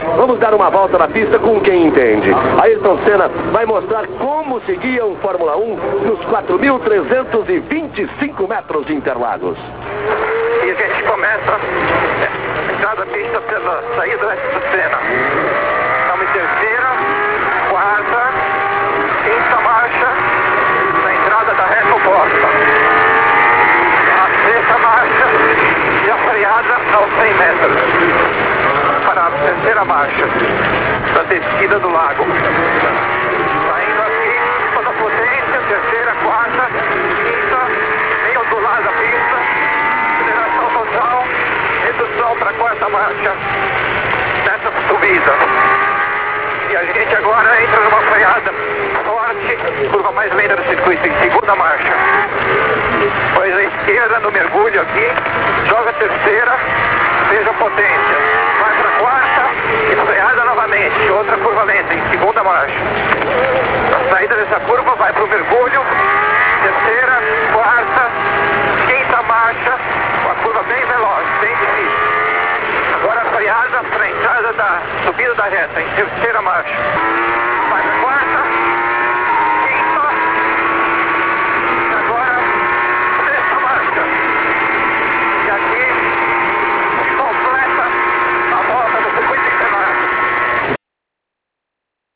VOLTA EM INTERLAGOS NARRADO POR SENNA DENTRO DO CARRO